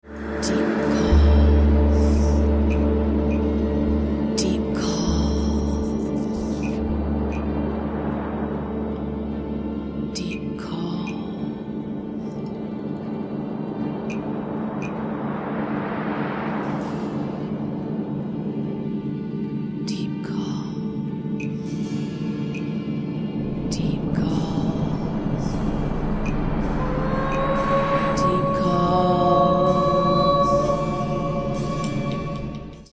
live prophetic worship recording